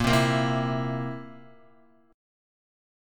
A#sus2b5 chord